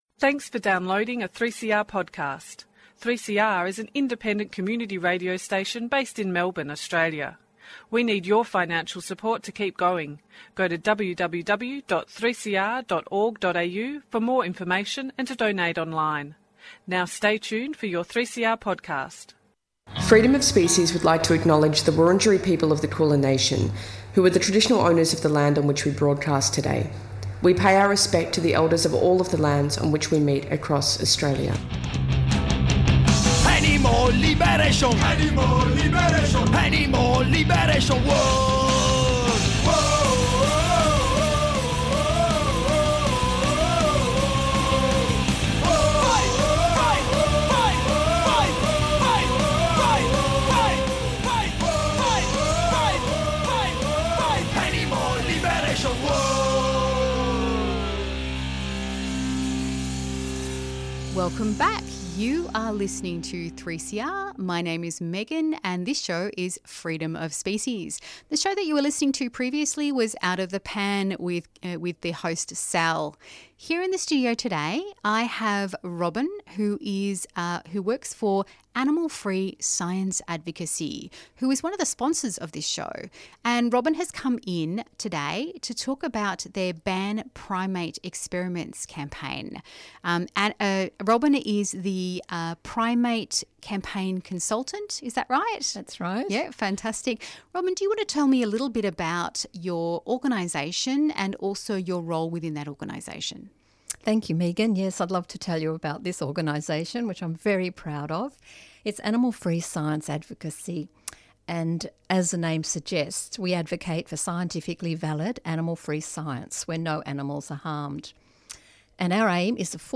Please note that for copyright reasons we cannot include the songs played on the show in the podcast.